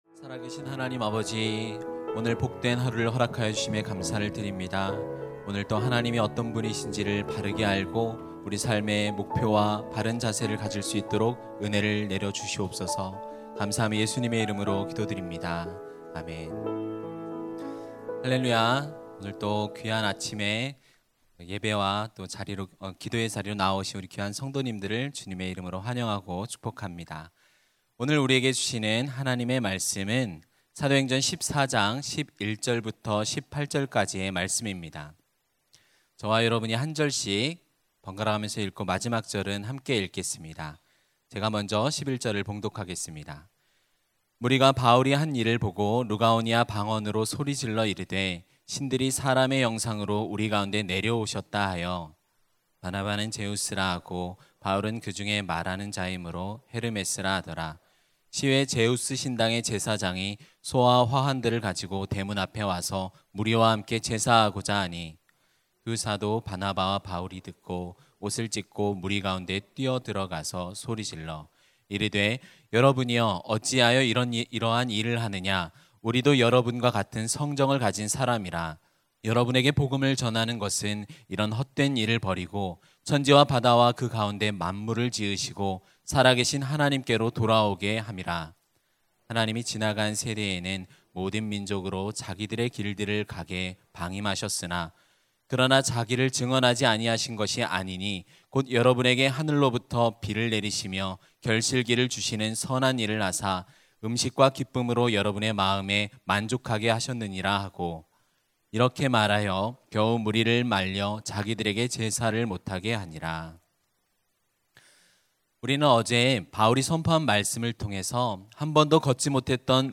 > 설교
[새벽예배]